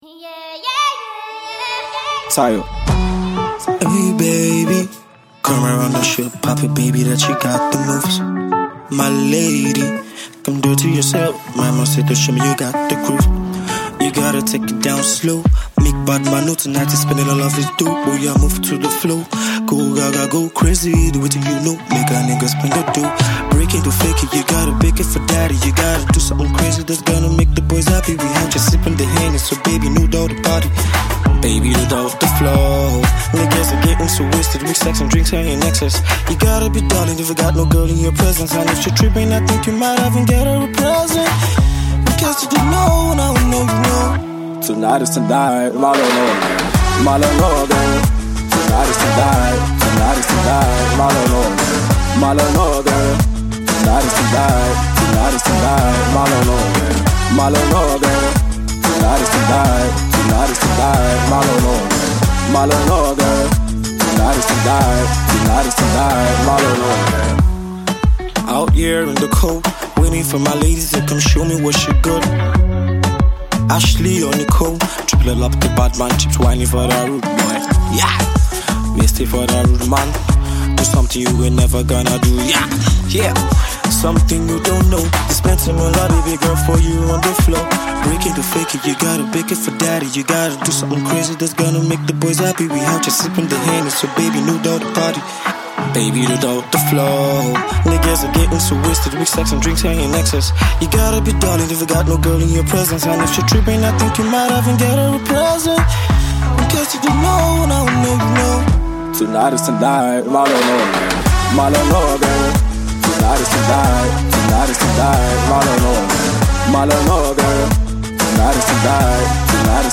Music Genre: RnB/Trap